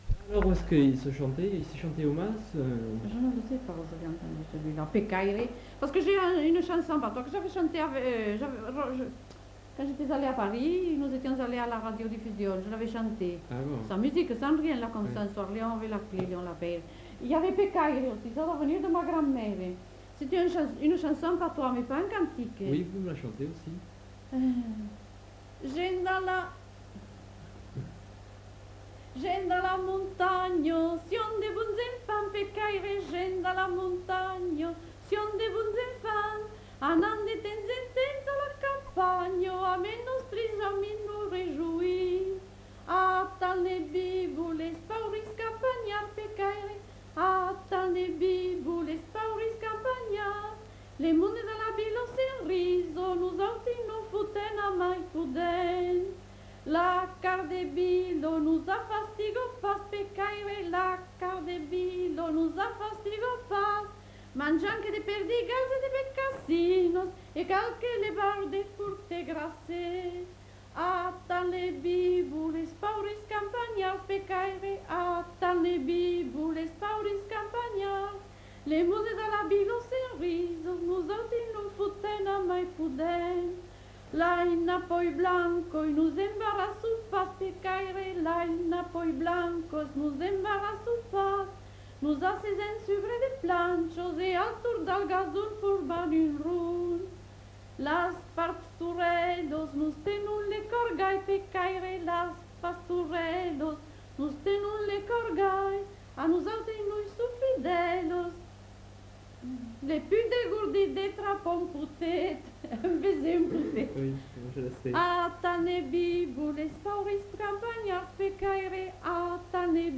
Lieu : Mas-Cabardès
Genre : chant
Effectif : 1
Type de voix : voix de femme
Production du son : chanté
Classification : chanson identitaire